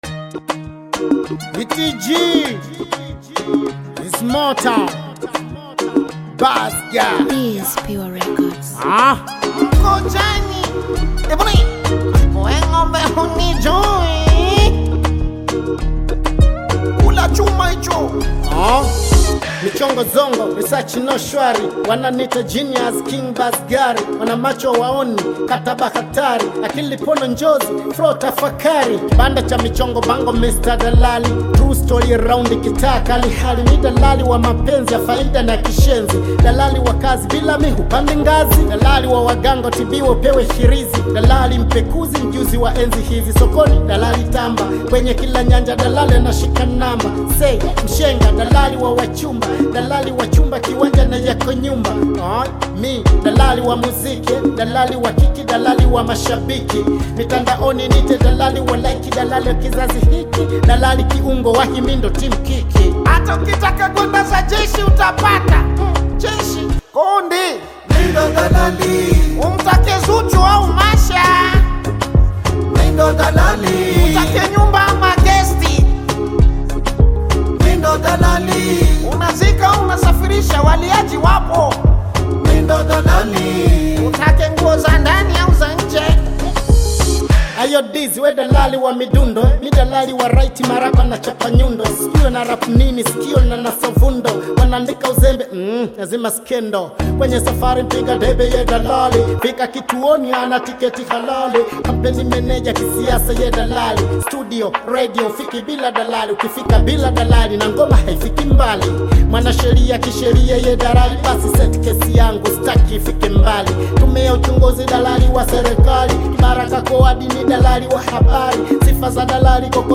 parody song
lighthearted and entertaining Bongo Flava track